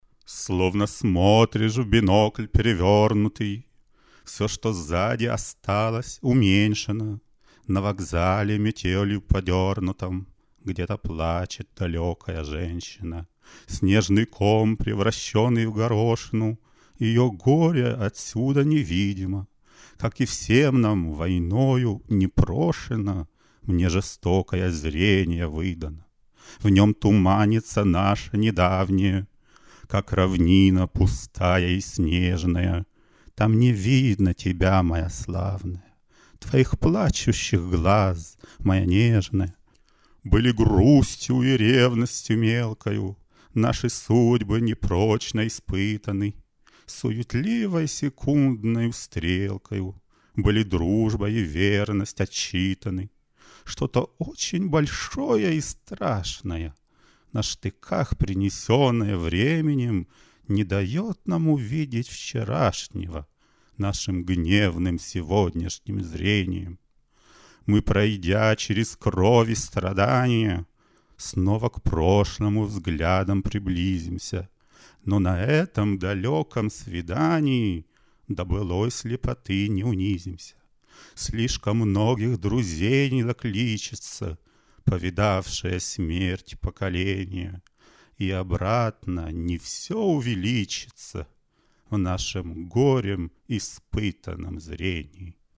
Описание: Стихи Константина Симонова в моей зачитке.